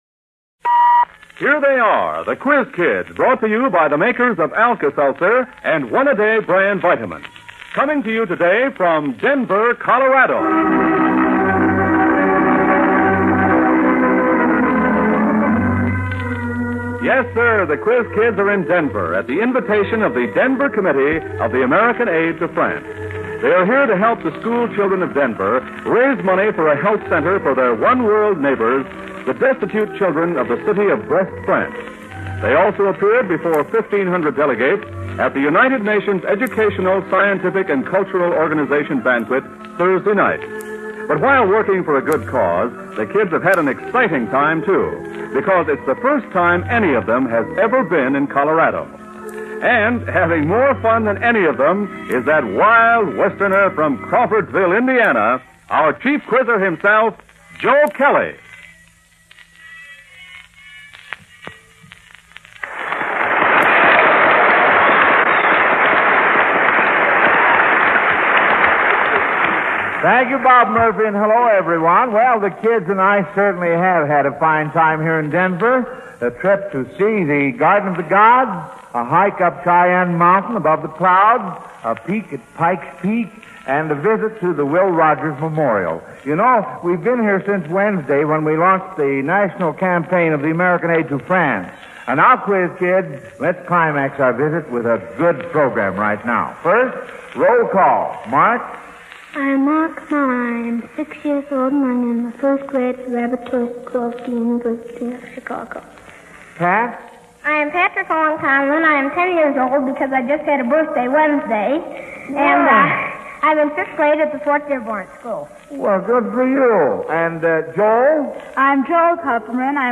The Quiz Kids Radio Program
The Quiz Kids Radio Program From Denver, Colorado, May 18, 1947